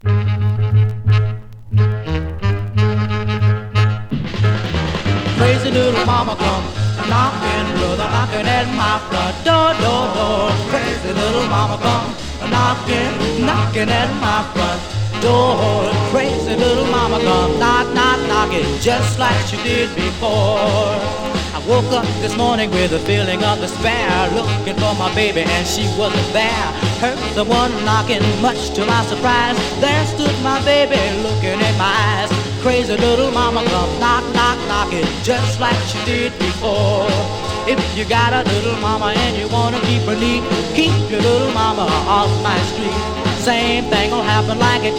勢いを感じるボーカルワークと。
Doo Wop, Rhythm & Blues　UK　12inchレコード　33rpm　Mono